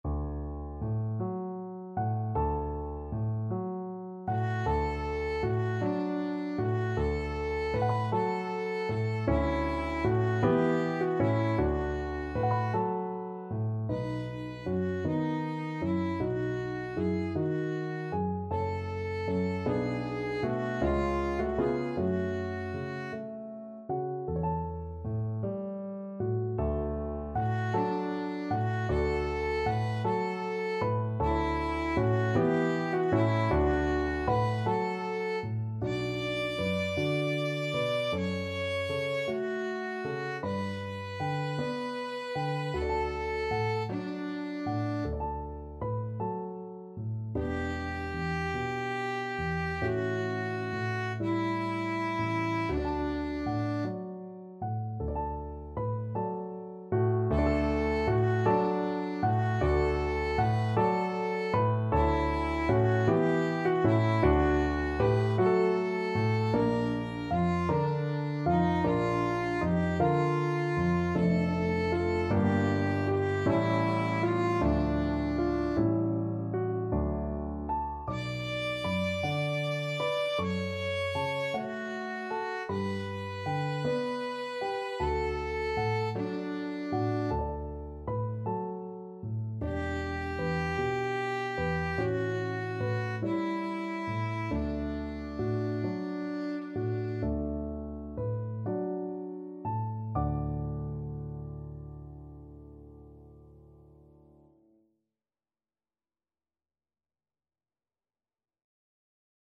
6/8 (View more 6/8 Music)
~. = 52 Allegretto
Classical (View more Classical Violin Music)